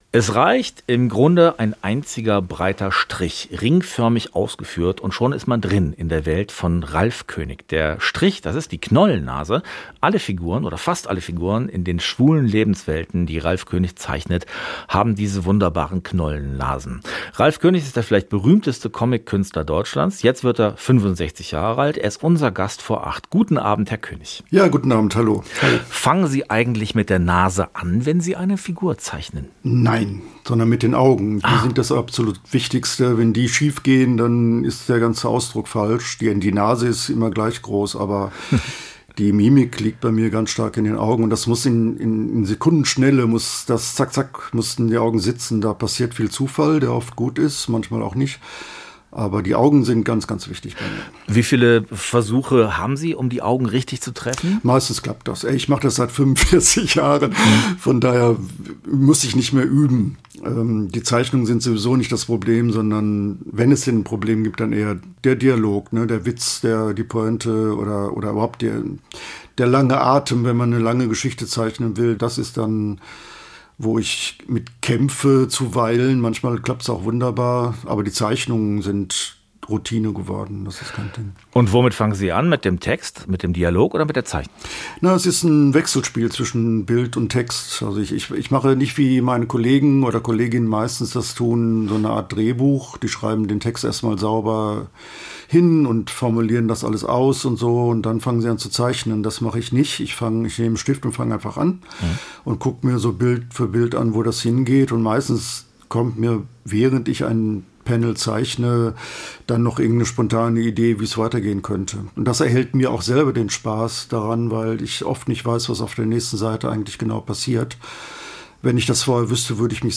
Comic-Chronist der schwulen Szene: Ralf König im Gespräch
Seine Knollennasen erzählen schwules Leben in Comics: Im Interview blickt Ralf König auf eine Karriere, die mehr ist als nur "Der bewegte Mann".